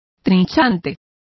Complete with pronunciation of the translation of carver.